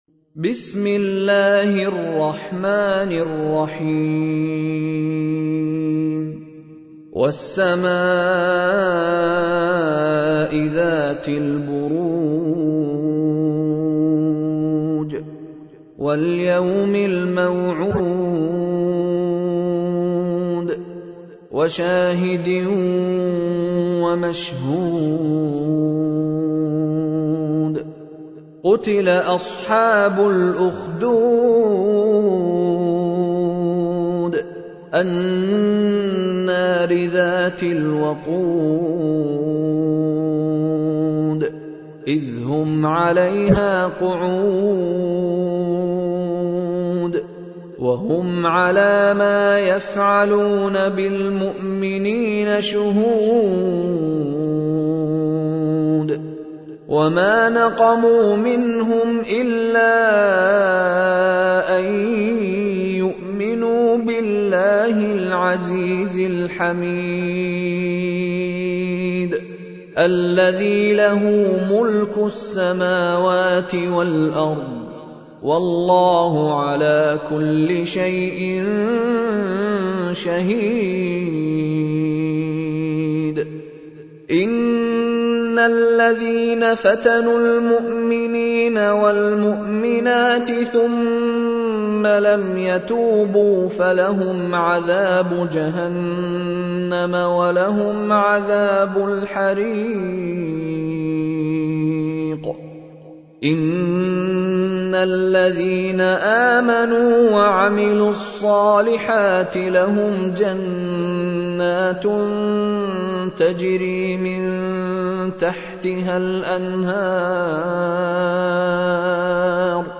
تحميل : 85. سورة البروج / القارئ مشاري راشد العفاسي / القرآن الكريم / موقع يا حسين